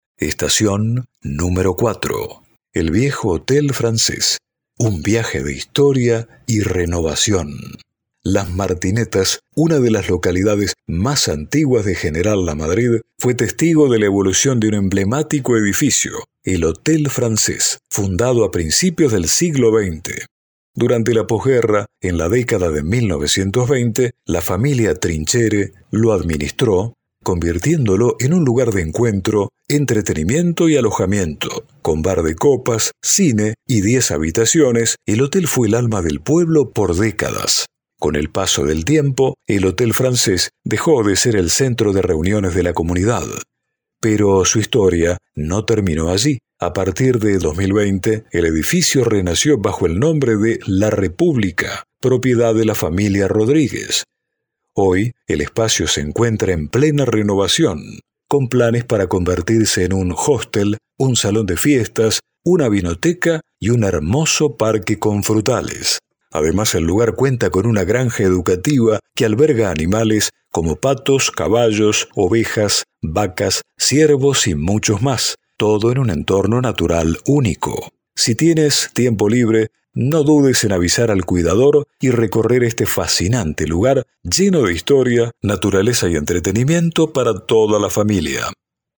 AUDIO-GUIA-MARTINETAS-ESTACION-CUATRO.mp3